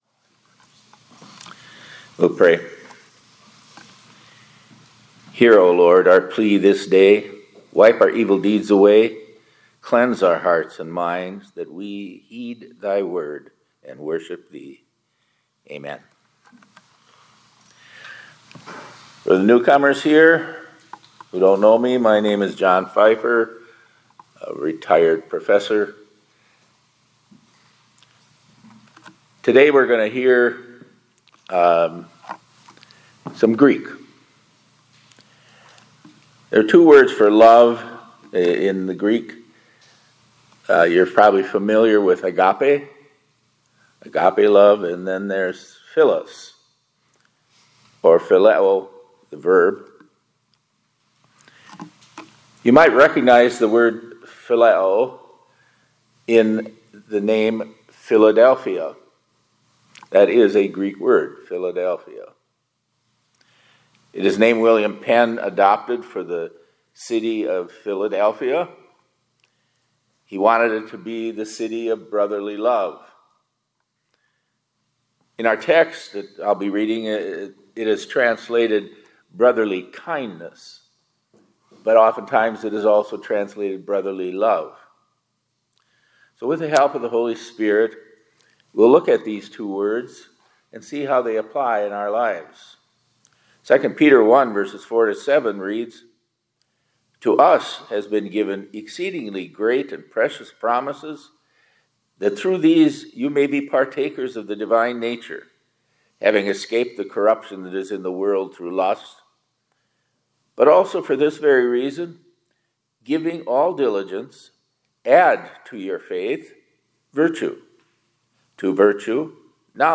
2025-08-27 ILC Chapel — Let This Be Your Year…